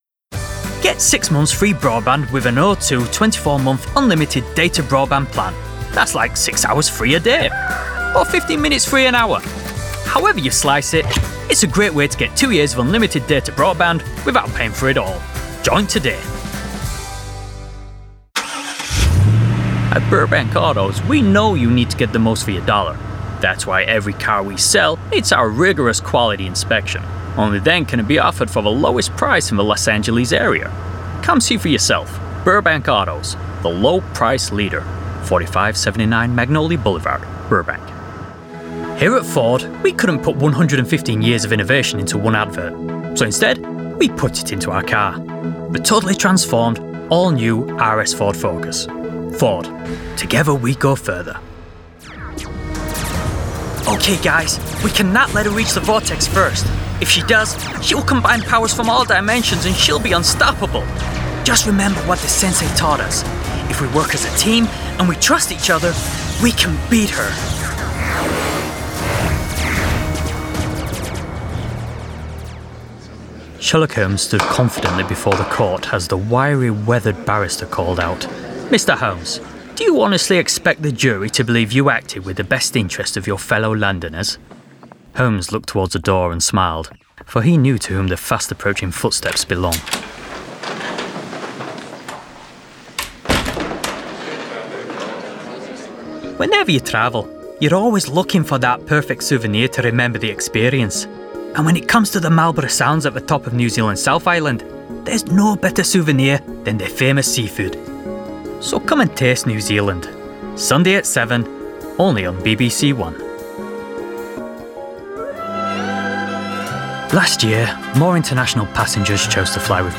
Demo
Adult
british rp | character